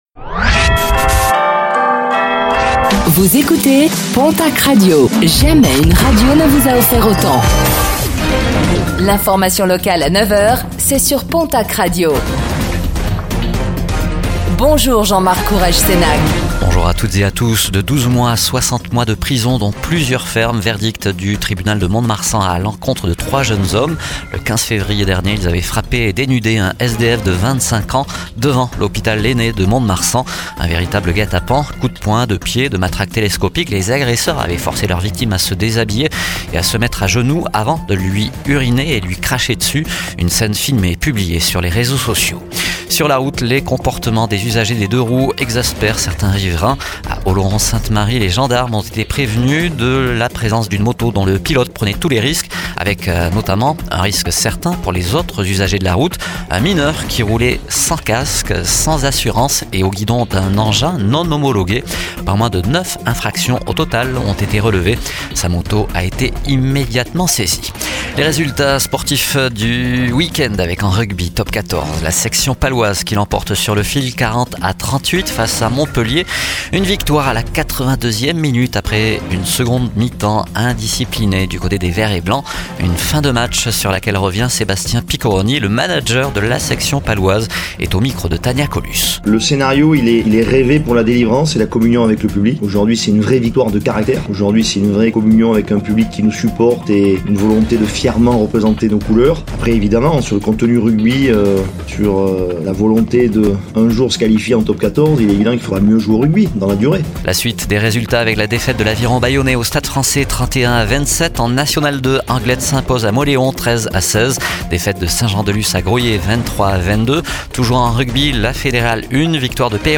Réécoutez le flash d'information locale de ce lundi 24 mars 2025